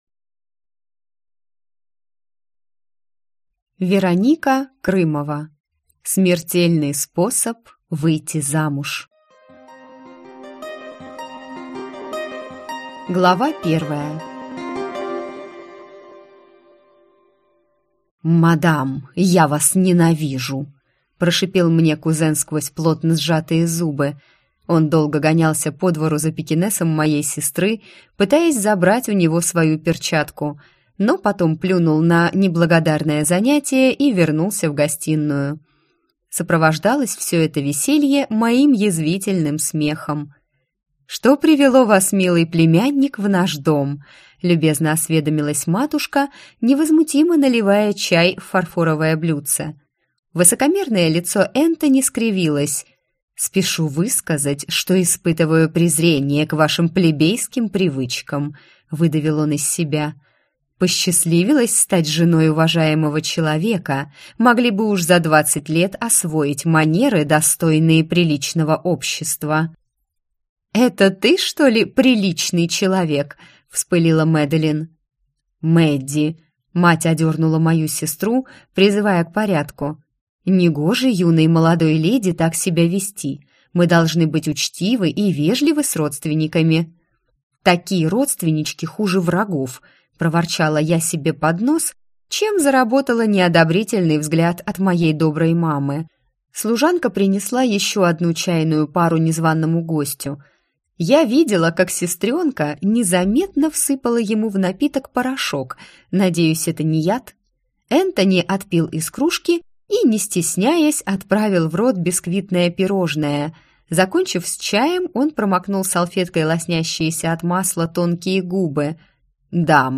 Аудиокнига Смертельный способ выйти замуж | Библиотека аудиокниг